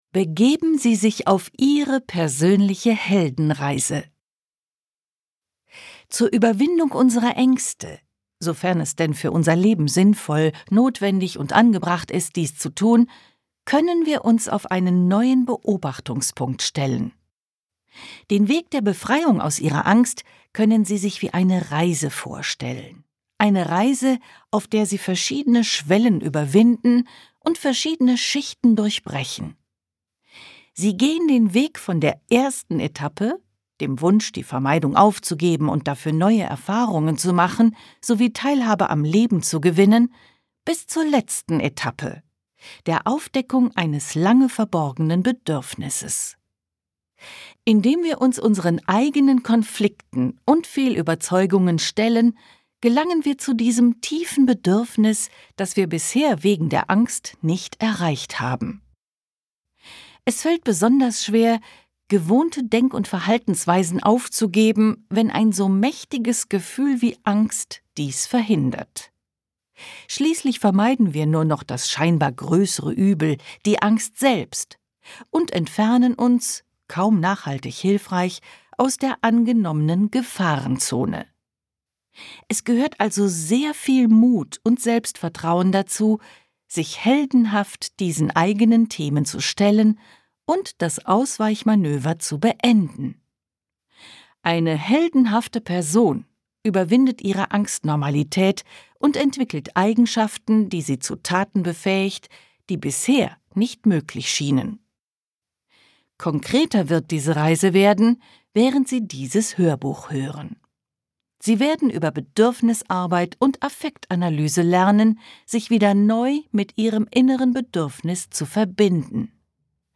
Gekürzte Fassung